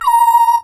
crunk efx8.wav